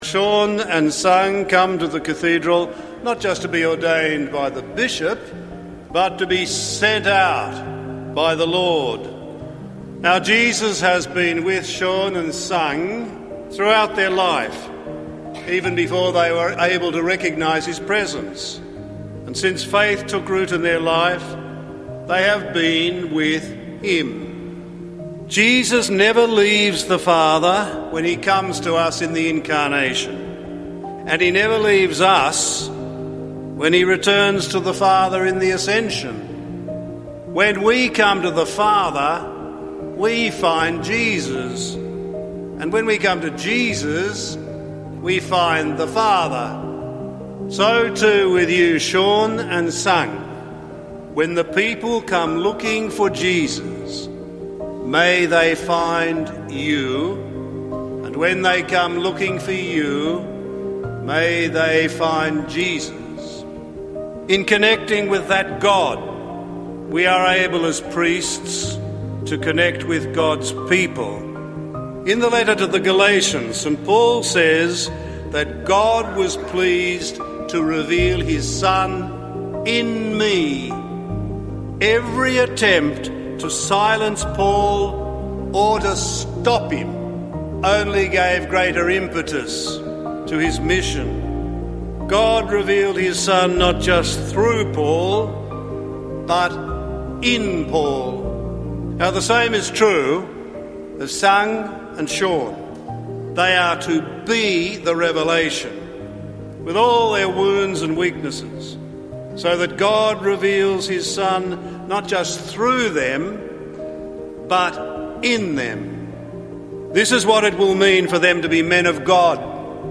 Priesthood Ordination Highlights 2:55